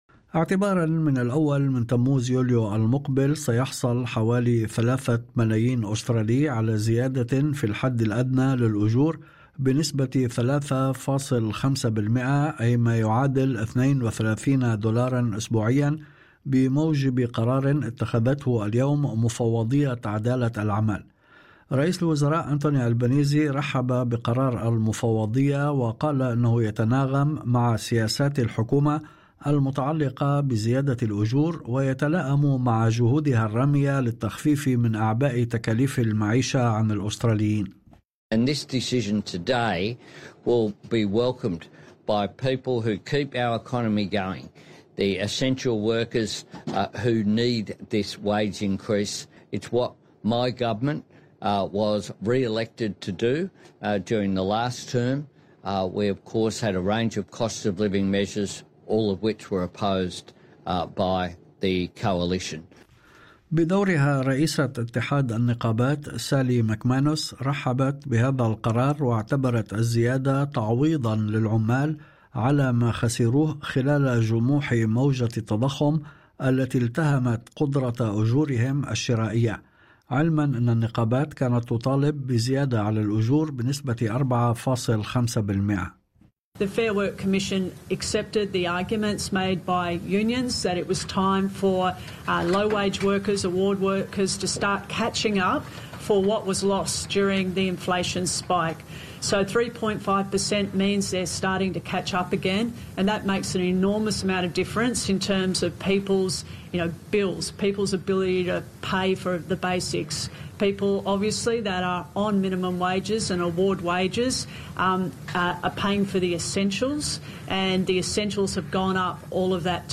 يمكنكم الاستماع الى النشرة الاخبارية كاملة بالضغط على التسجيل الصوتي أعلاه.